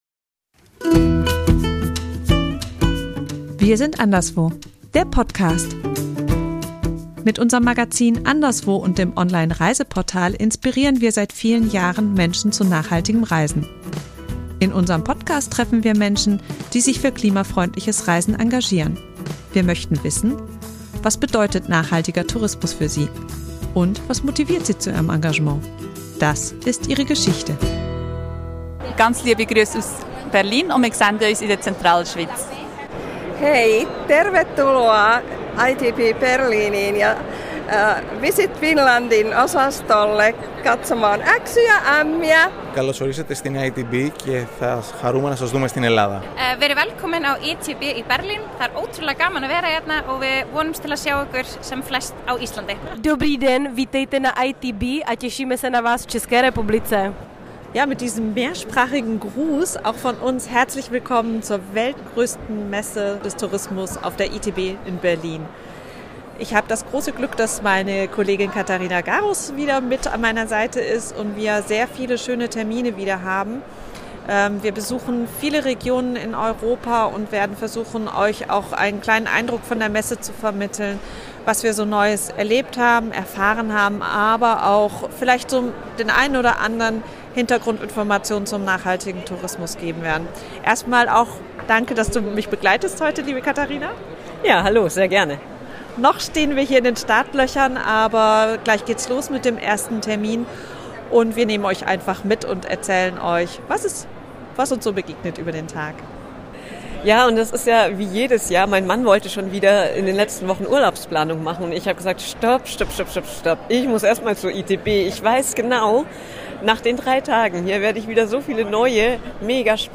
Wir nehmen euch mit zur ITB nach Berlin und stellen euch nachhaltige Reiseziele vor, die sich perfekt für einen klimafreundlichen Urlaub eignen.